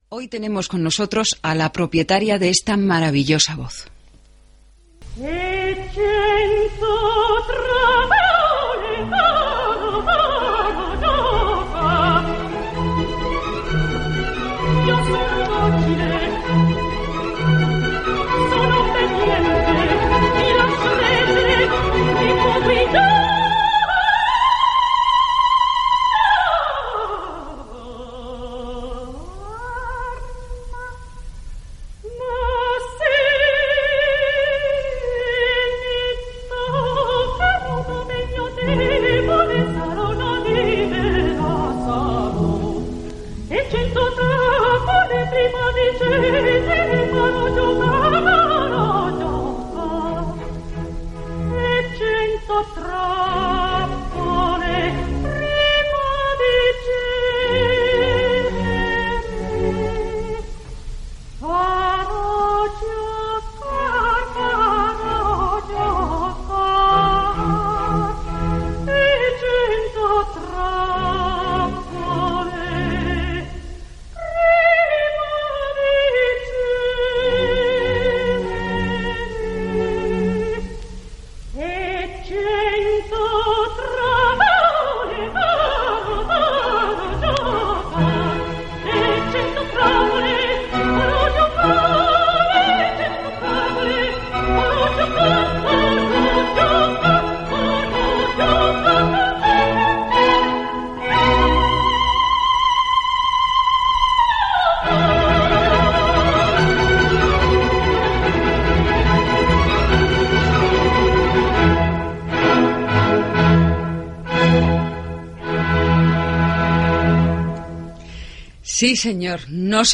Entrevista a la mezzosoprano Teresa Berganza que havia actuat al teatre de l'Scala de Milan Gènere radiofònic Musical